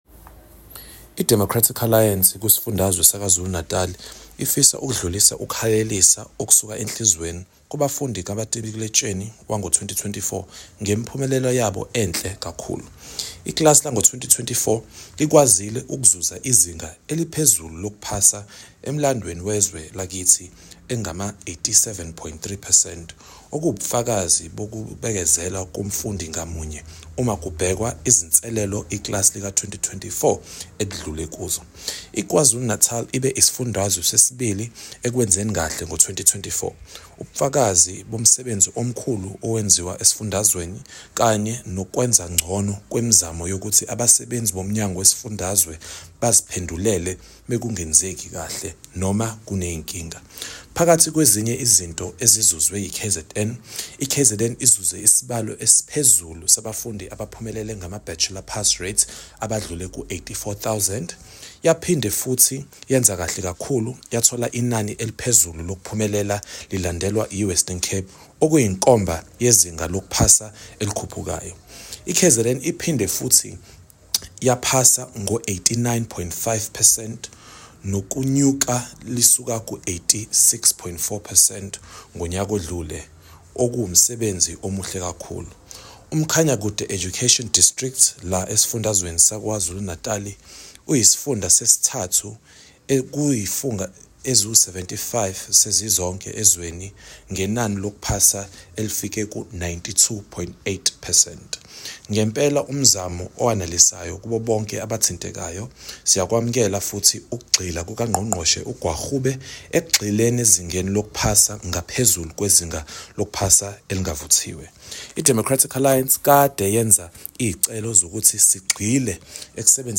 Issued by Sakhile Mngadi, MPL – DA KZN Spokesperson on Education
Note to editors: Please find attached soundbites in English and